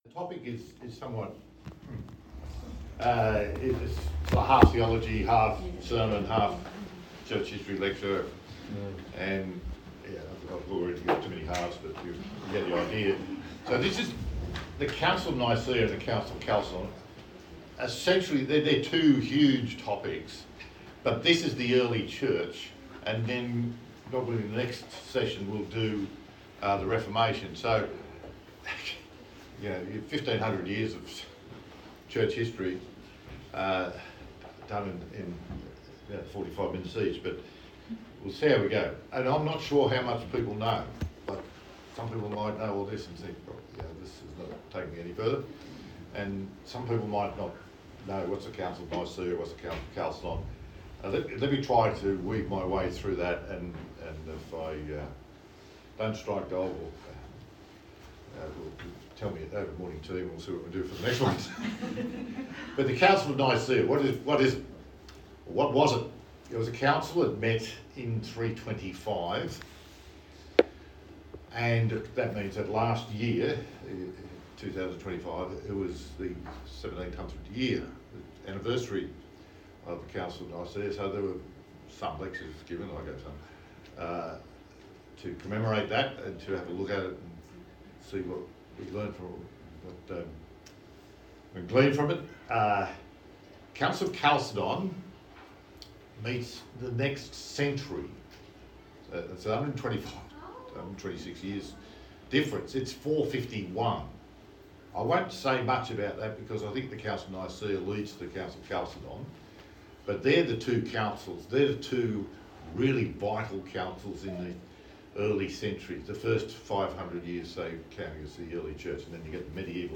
Service Type: Church Camp Talk